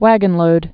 (wăgən-lōd)